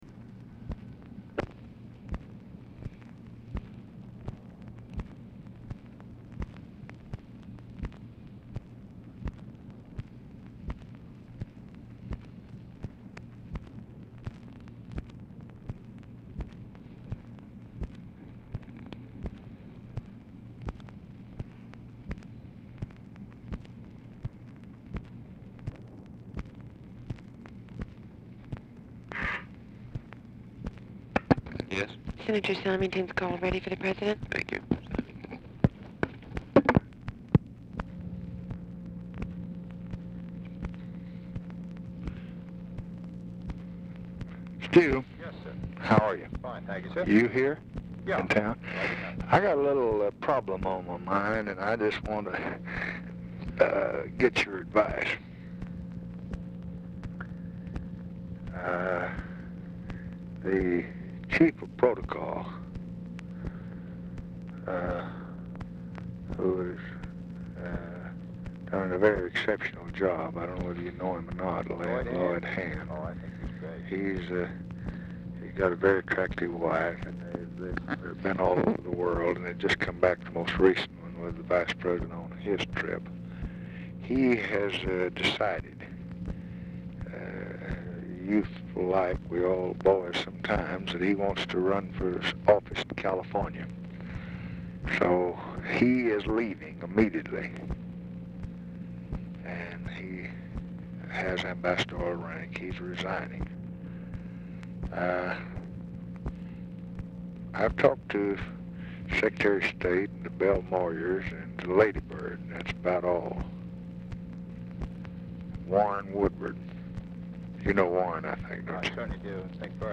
Telephone conversation # 9899, sound recording, LBJ and STUART SYMINGTON, 3/18/1966, 3:35PM | Discover LBJ
Format Dictation belt
Location Of Speaker 1 Oval Office or unknown location
Specific Item Type Telephone conversation